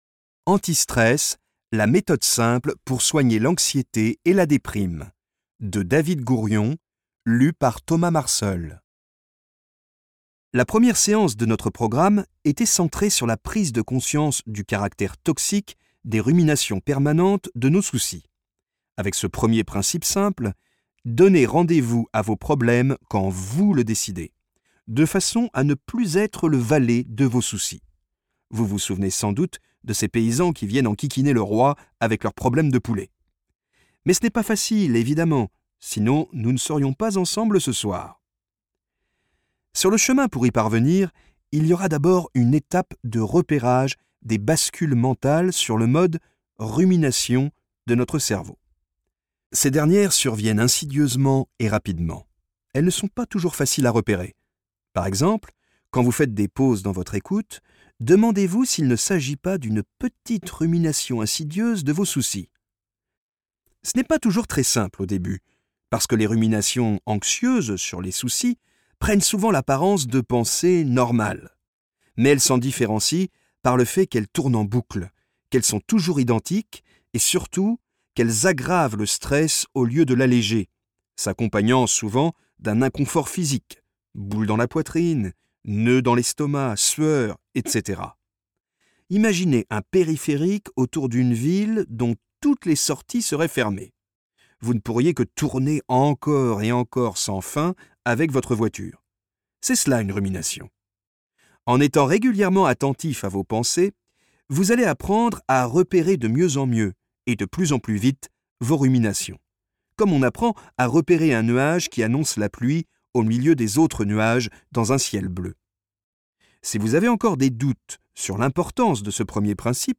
Ce livre audio est une méthode simple et rapide pour apprendre à gérer son stress.